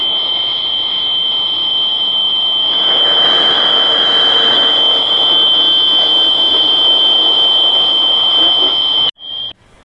Звуки радио и помехи
Свистяще-шипящие радио-помехи
interference2.wav